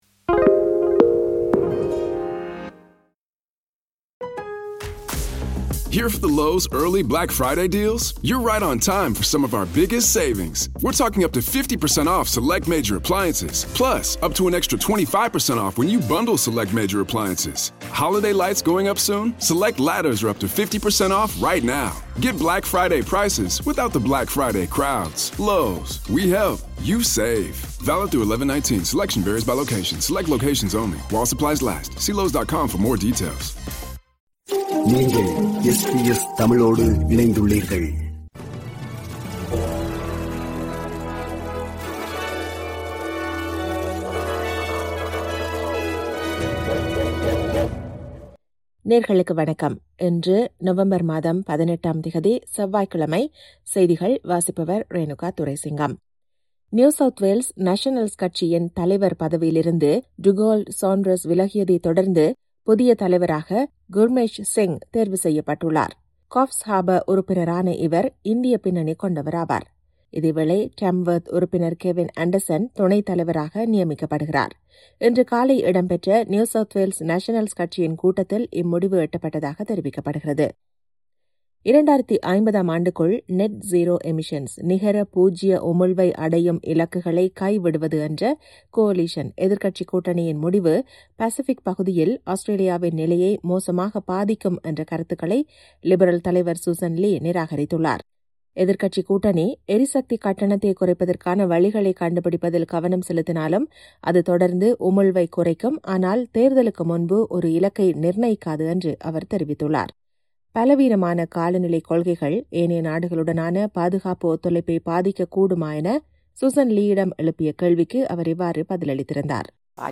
SBS தமிழ் ஒலிபரப்பின் இன்றைய (செவ்வாய்க்கிழமை 18/11/2025) செய்திகள்.